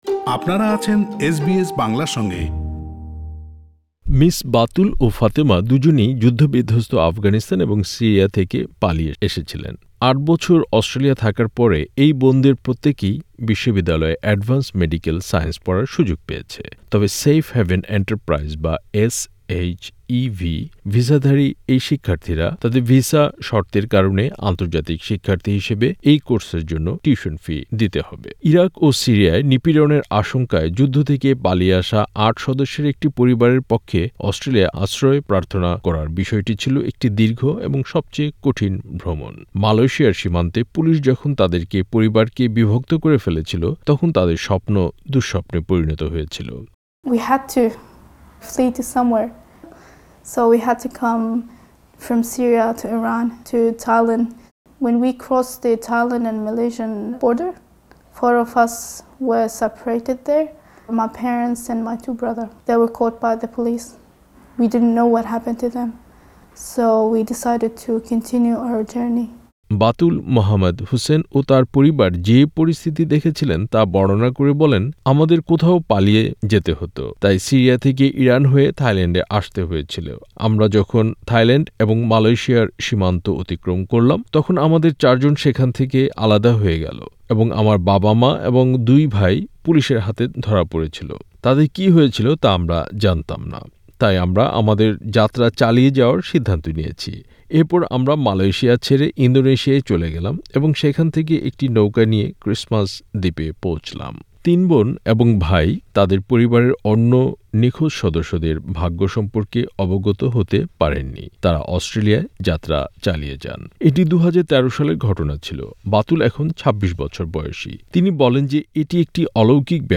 আশা-নিরাশার গল্প নিয়ে একটি প্রতিবেদন।